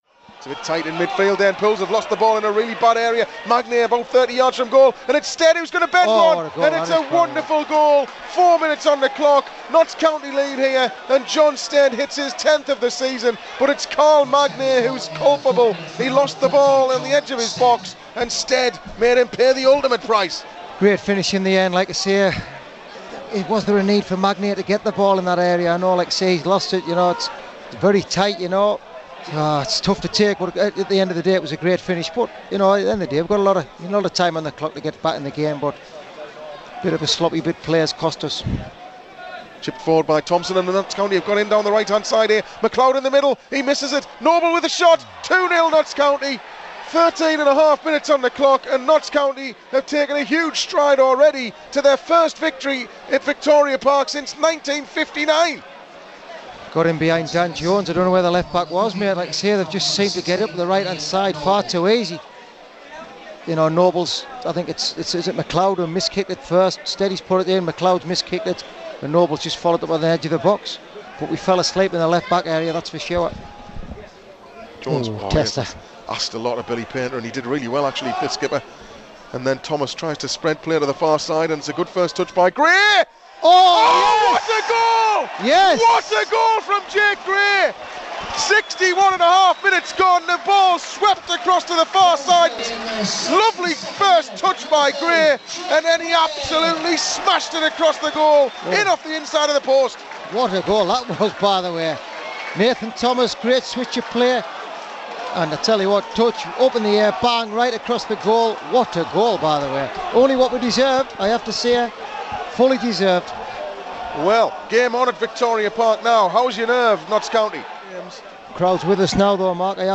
Listen back to how the drama of Tuesday night's game sounded as it unfolded on Pools PlayerHD's live coverage.